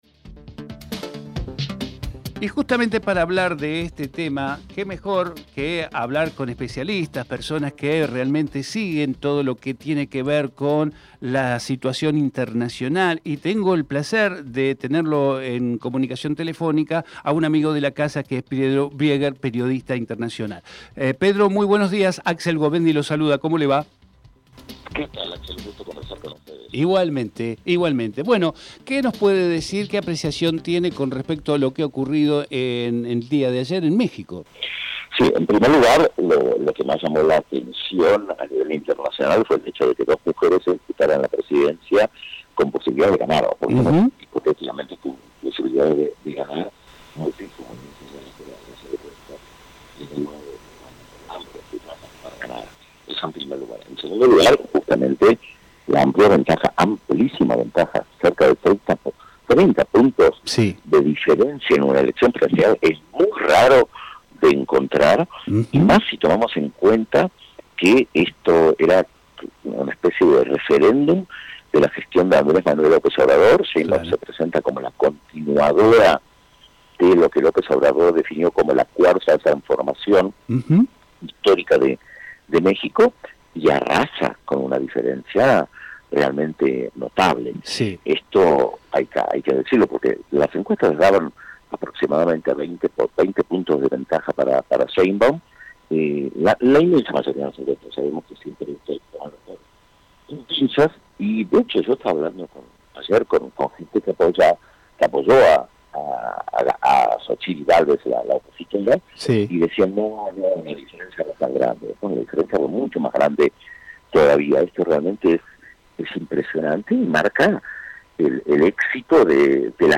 TERRITORIO SUR - PEDRO BRIEGER Texto de la nota: Compartimos con ustedes la entrevista realizada en Territorio Sur a Pedro Brieger, Periodista Internacional Archivo de audio: TERRITORIO SUR - PEDRO BRIEGER Programa: Territorio Sur